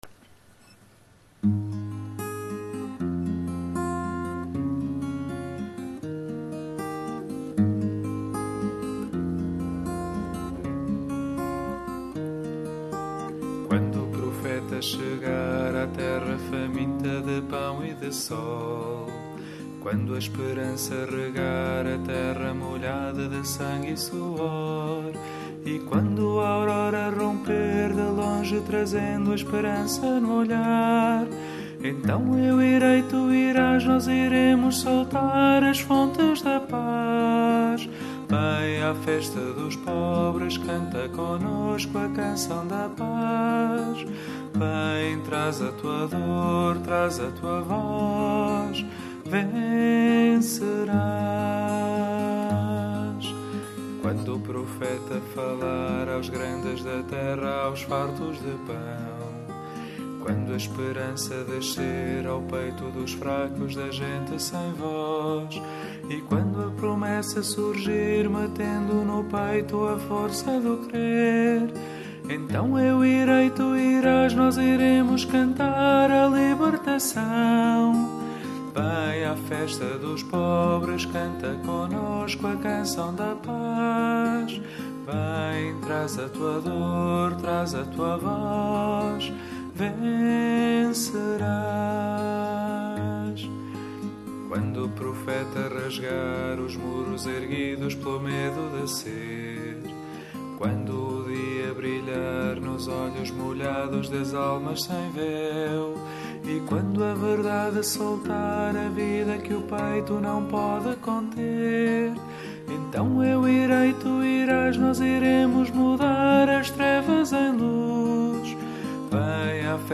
Foram gravados alguns cânticos em formato MP3 apenas para facilitar a aprendizagem dos mesmos. Sendo uma execução não profissional, a versão gravada pode não corresponder à versão original dos cânticos.